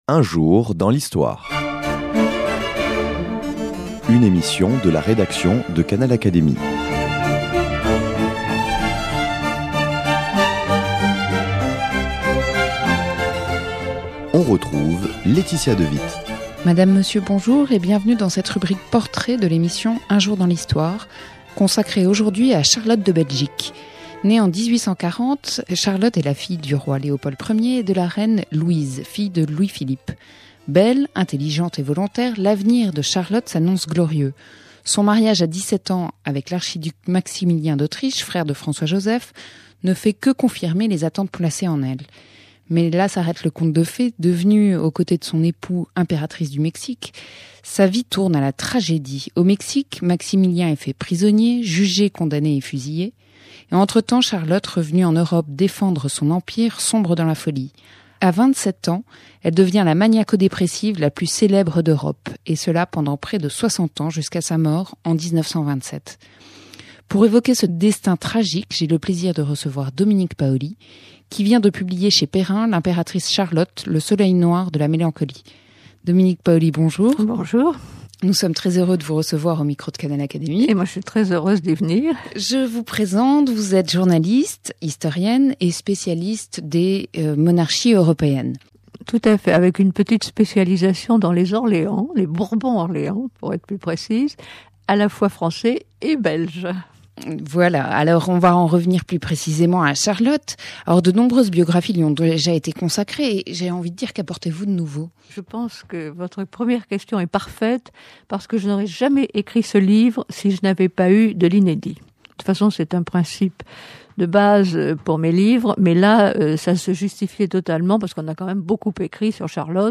Ecoutons-la nous présenter cette personnalité complexe, souvent mal connue.